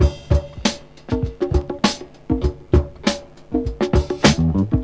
• 99 Bpm Drum Loop D Key.wav
Free drum loop sample - kick tuned to the D note. Loudest frequency: 1490Hz
99-bpm-drum-loop-d-key-tQI.wav